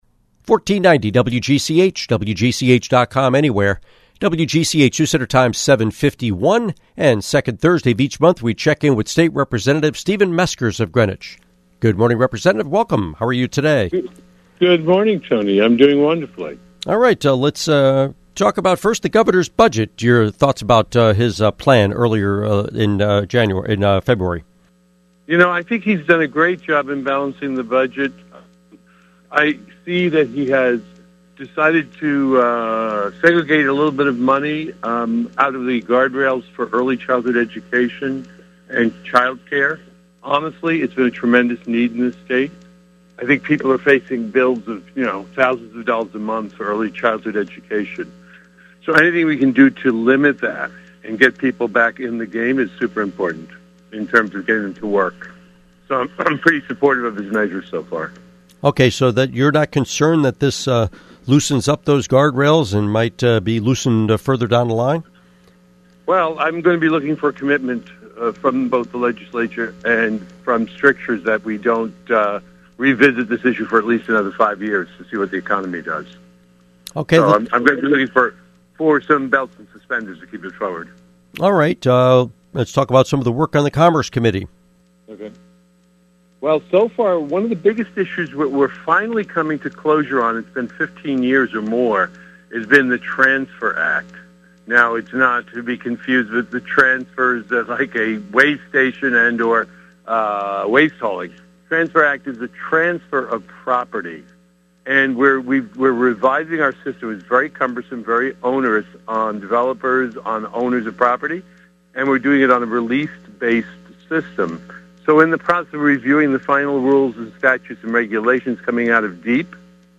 Interview with State Representative Stephen Meskers